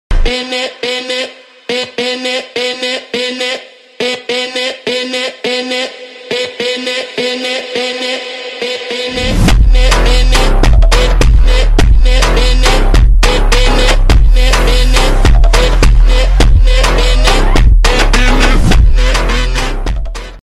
Brazilian phonk